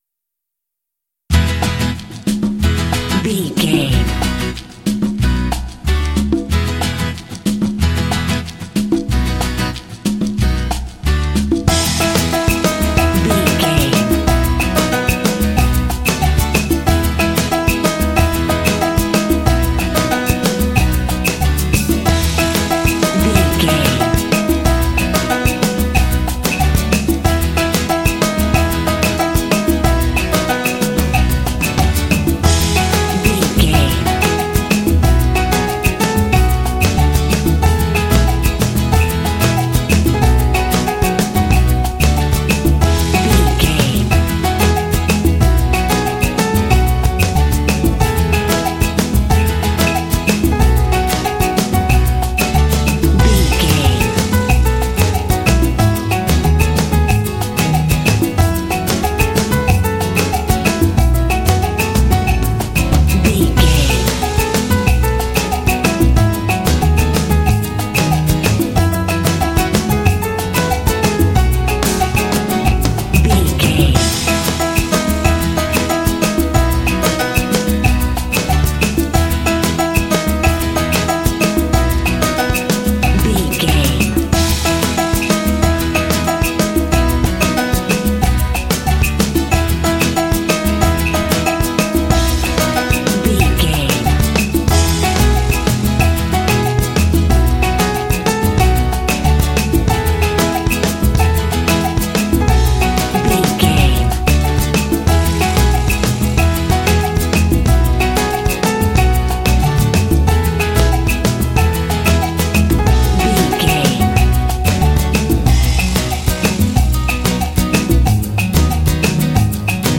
Uplifting
Ionian/Major
happy
festive
lively
bouncy
energetic
playful
percussion
acoustic guitar
piano
drums
bass guitar
jazz